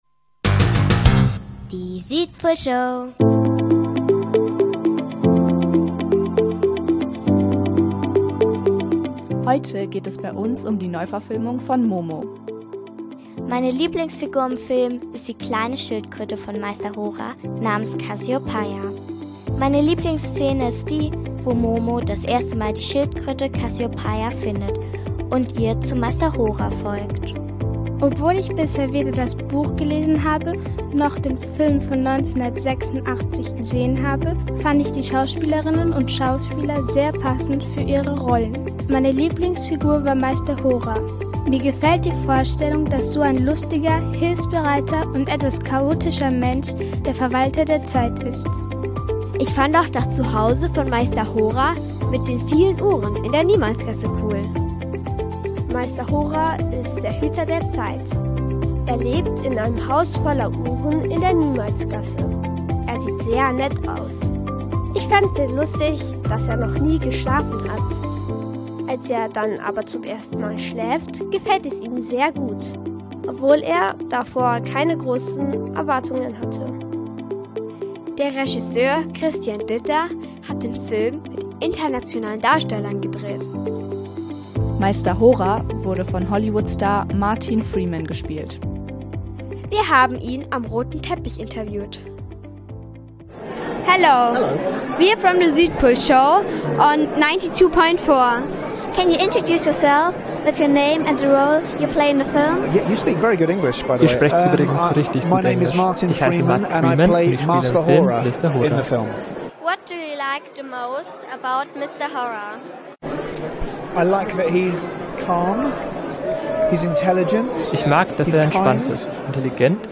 Südpolshowreporter bei der Filmpremiere in München.